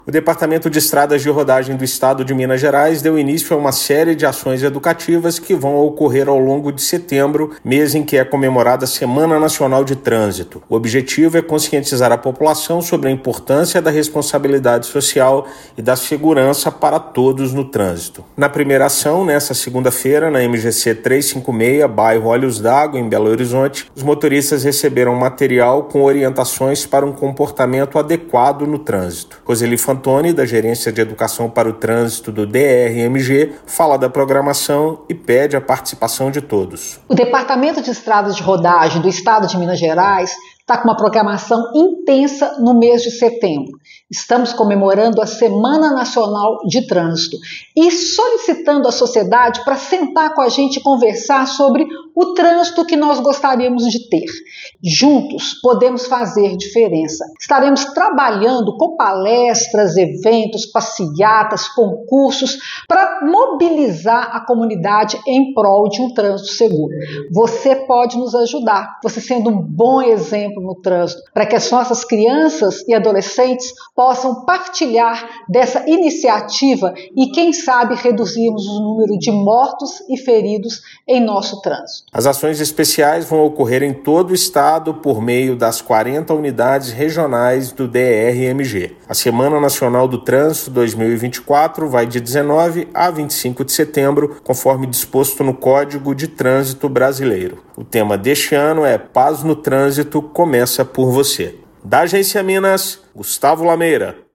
Autarquia realiza palestras, atividades culturais e de conscientização em todo o estado para celebrar a Semana Nacional de Trânsito 2024. Ouça matéria de rádio.